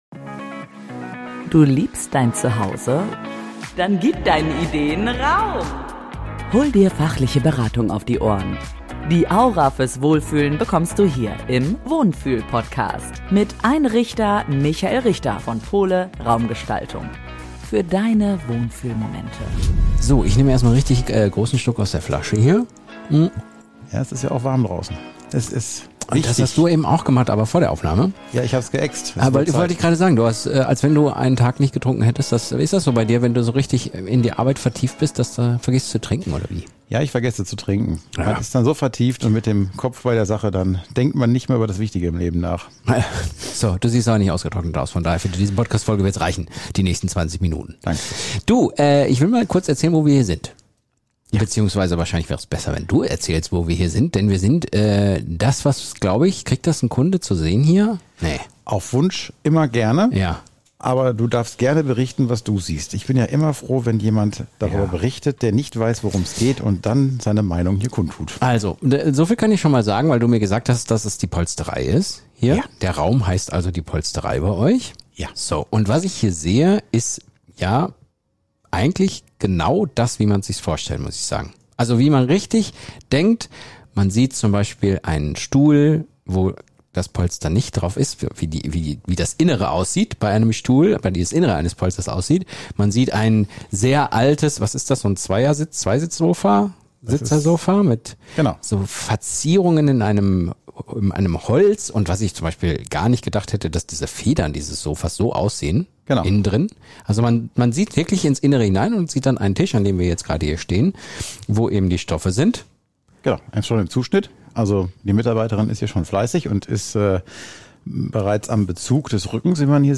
Ein akustischer Werkstattbesuch voller Leidenschaft für echtes Handwerk. Und ein Plädoyer dafür, Dinge nicht einfach wegzuwerfen, sondern zu bewahren – für das Gefühl, Geschichte und richtig gutes Sitzen.